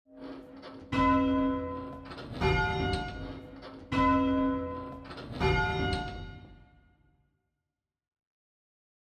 Augmented 4th Intervals
However, it does not sound like half an octave and only aligns relatively infrequently.
The sound is something we will not find as natural or pleasant to listen to… it is quite dissonant and most will find it difficult to pitch or sing accurately.